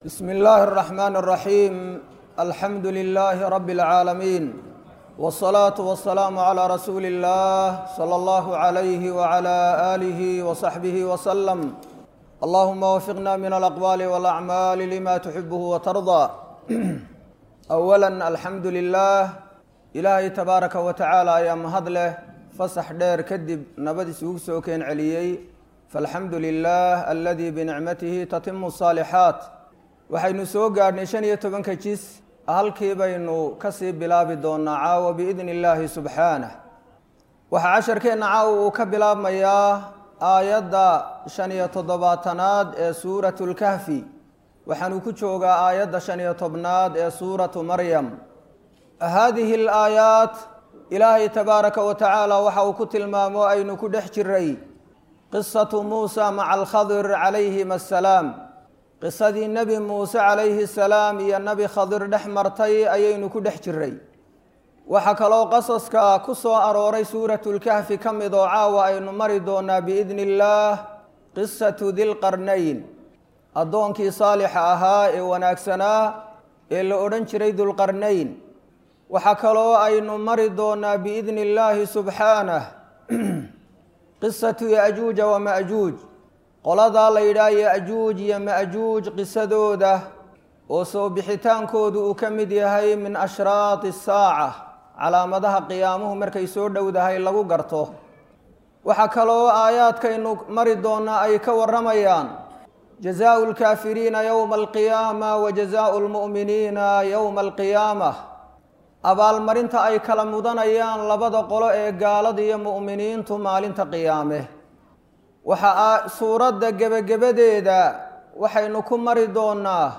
Waa Tafsiir Kooban Oo Ka Socda Masjid Ar-Rashiid – Hargaisa ²⁰²² / ¹⁴⁴⁴